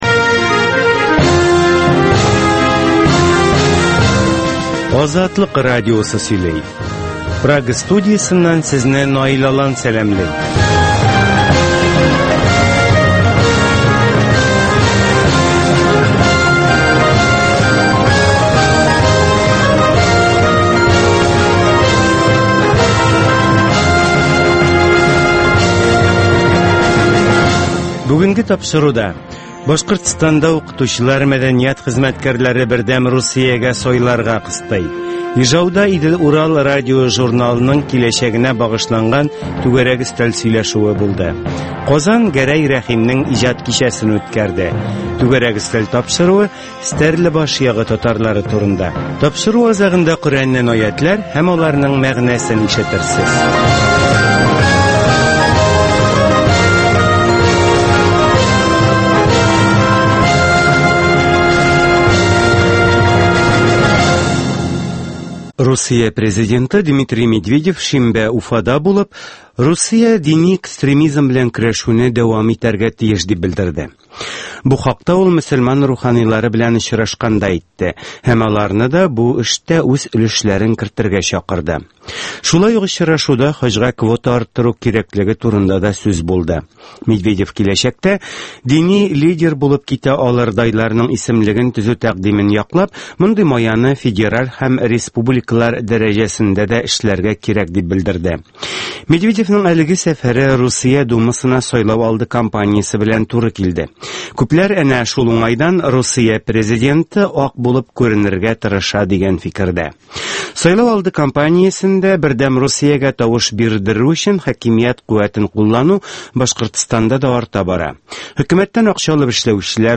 Азатлык узган атнага күз сала - Башкортстаннан атналык күзәтү - Татар дөньясы - Түгәрәк өстәл сөйләшүе - Коръәннән аятләр һәм аларның мәгънәсе